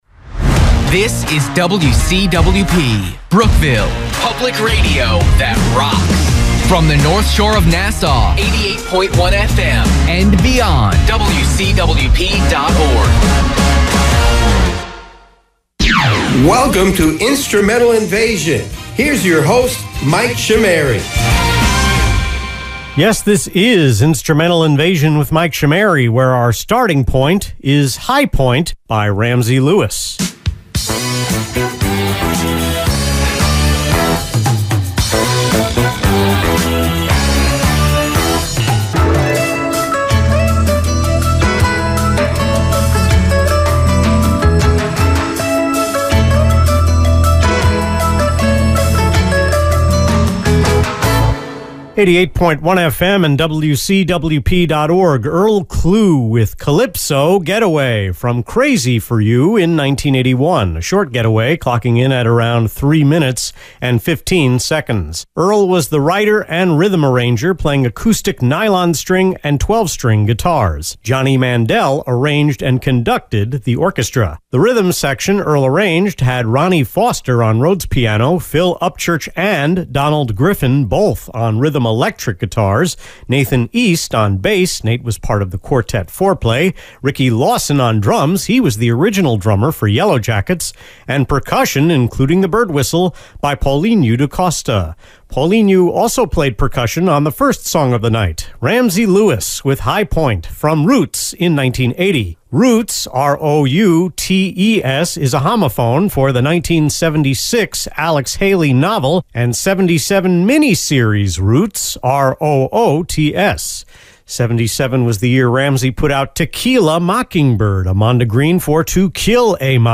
The November 9 Instrumental Invasion on WCWP was recorded on September 26 (two segments) and 27 (four segments).
I opted against remixing this show and the last two.